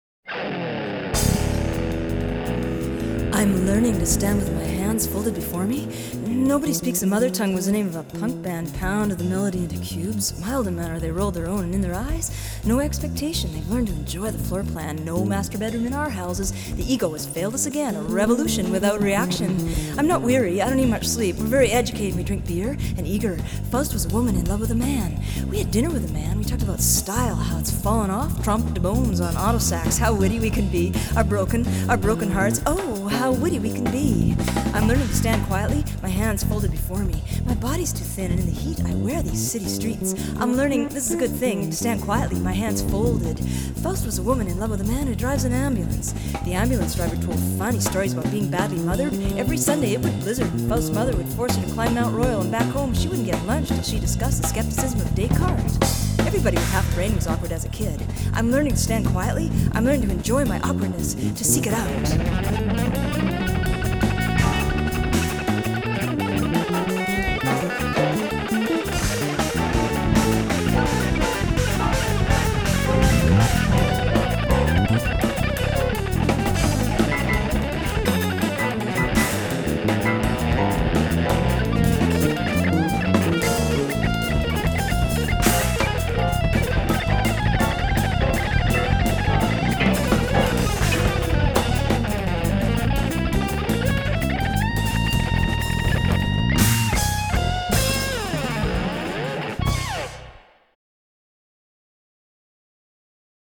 Recorded at CBC Studio 11 in Winnipeg